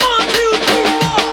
bb2_90_drum_fill_count
bb2_90_drum_fill_count.wav